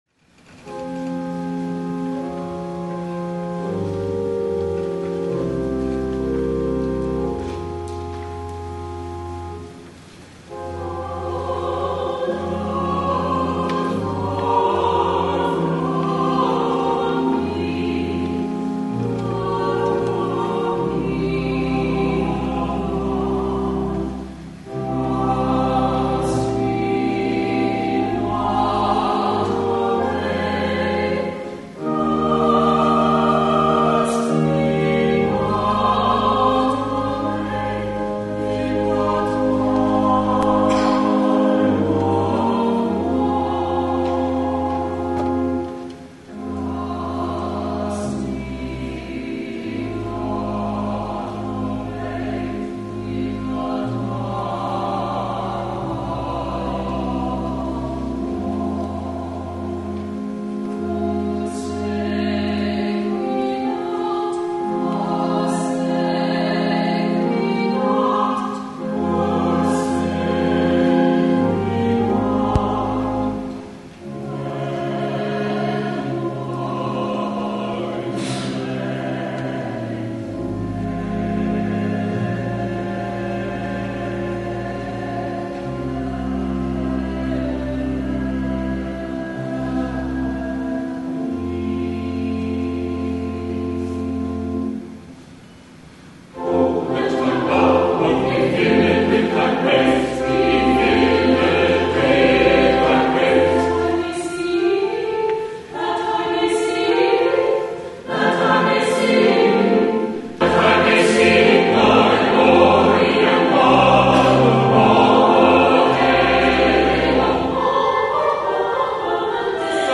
WORSHIP MARCH 6, 2011
THE ANTHEM